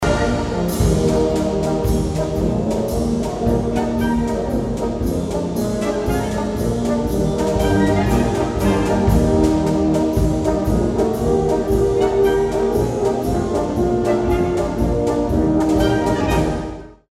Audiobeispiel eines Tenorhorn-/Baritonsatzes
Audiobeispiel Tenorhorn-/ Baritonsatz
tenorhorn-bariton.mp3